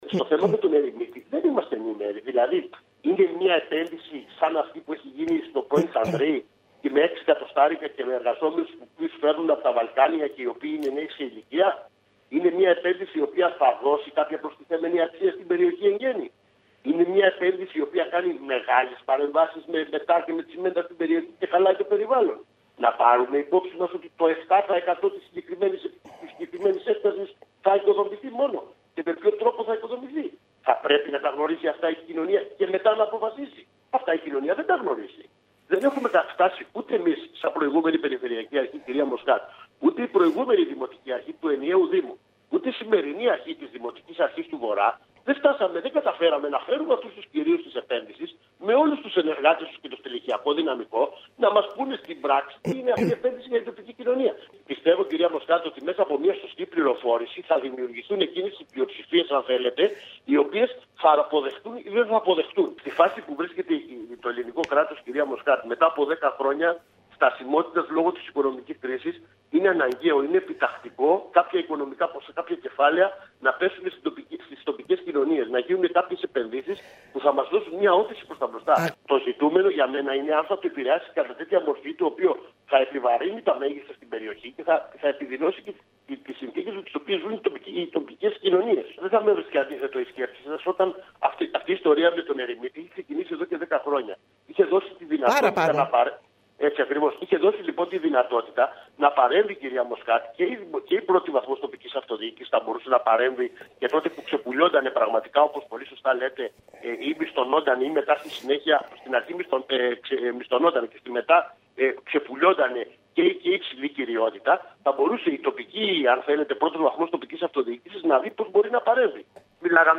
Mιλώντας στην ΕΡΤ Κέρκυρας ο βουλευτής του ΚΙΝΑΛ Κέρκυρας Δημήτρης Μπιάγκης αναφορικά με το θέμα του Ερημίτη, υπογράμμισε πως θα πρέπει οι πολίτες της Κέρκυρας να ενημερωθούν σωστά για τις επενδυτικές προθέσεις της εταιρείας και να μην σπεύδουν να καταδικάσουν την επένδυση συνολικά. Παράλληλα ο κος Μπιάγκης επέρριψε ευθύνες και στην τοπική αυτοδιοίκηση που δεν συνεργάστηκε τα προηγούμενα χρόνια με τους φορείς της Κέρκυρας προκειμένου να αποκτήσει τη συγκεκριμένη  έκταση.